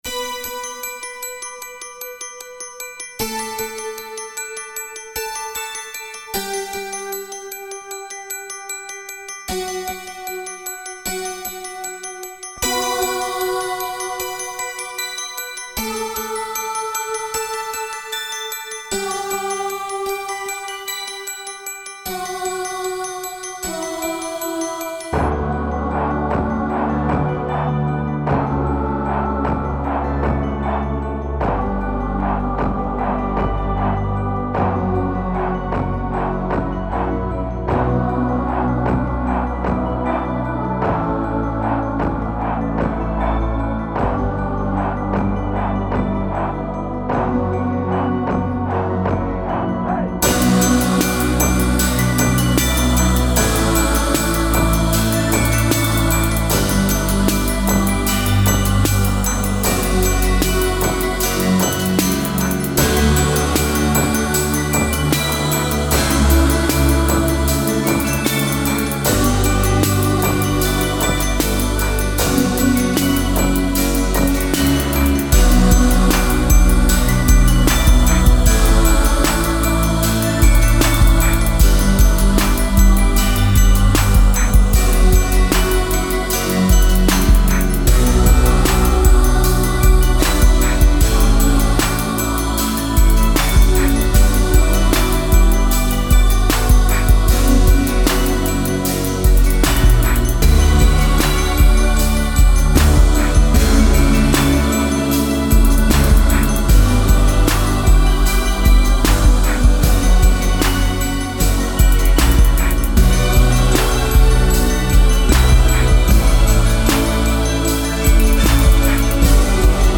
Beat Series